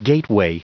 Prononciation du mot gateway en anglais (fichier audio)
Prononciation du mot : gateway